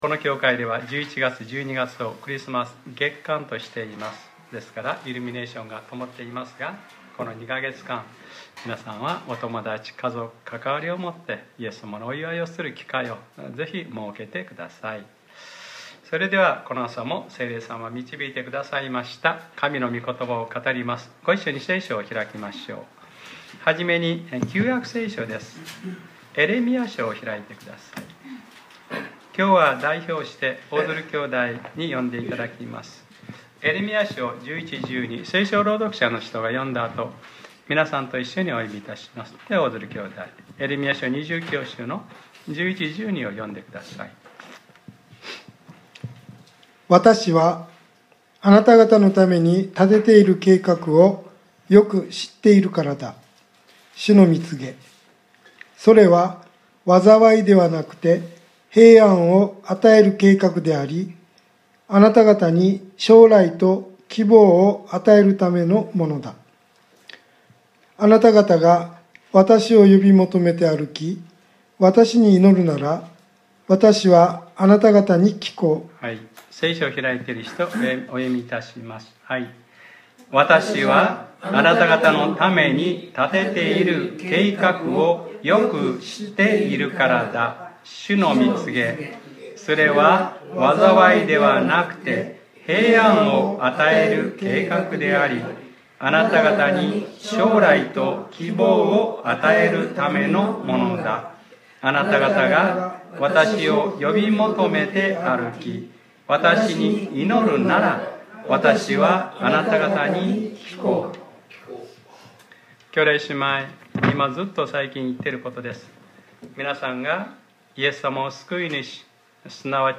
2019年11月03日（日）礼拝説教『弟子の歩み：みこころに従う』